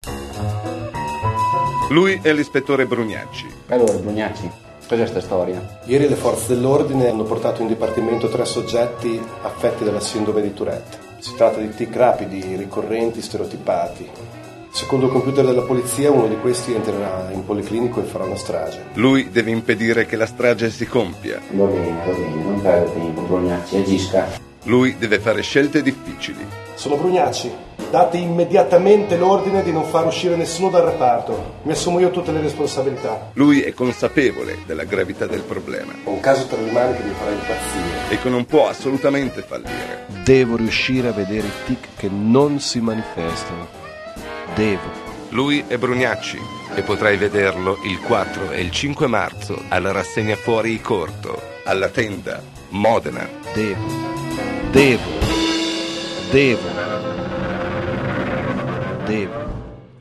trailer radiofonico.mp3
trailer_radiofonico.mp3